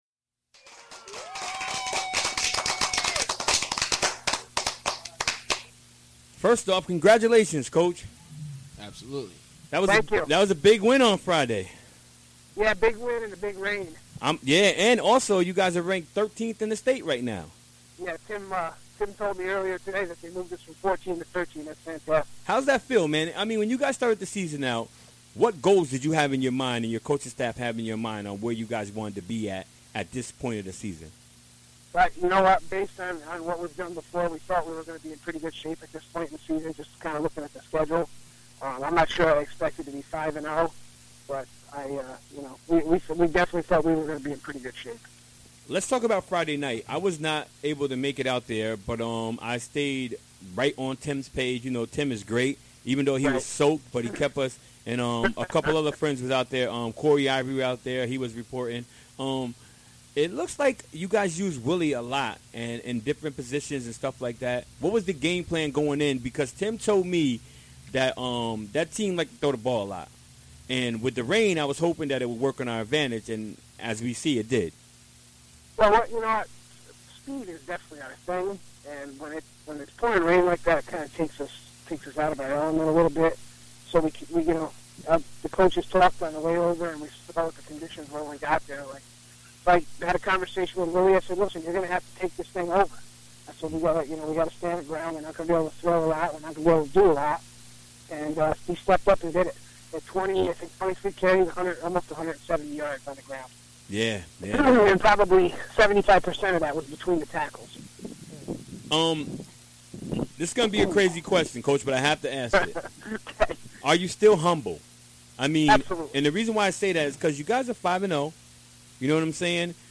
calls in to talk about their latest win
Recorded during the WGXC Afternoon Show Wednesday, October 5, 2016.